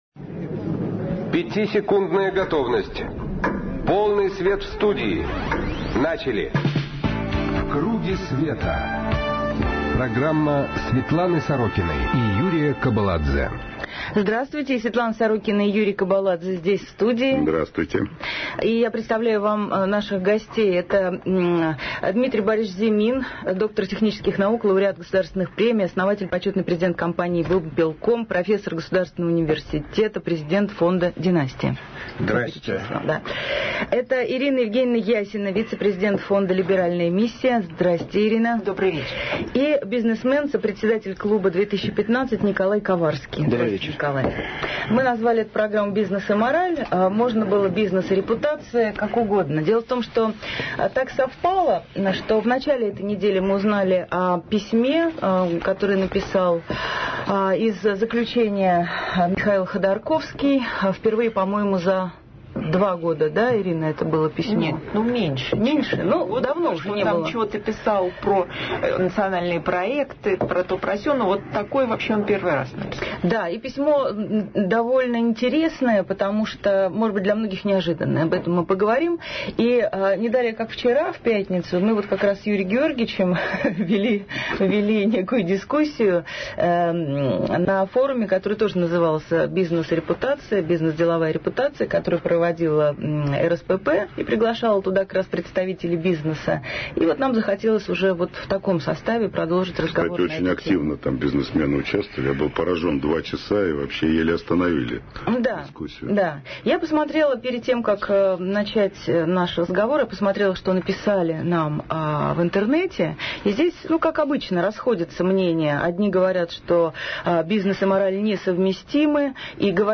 Светлана Сорокина: передачи, интервью, публикации
Светлана Сорокина и Юрий Кобаладзе здесь в студии.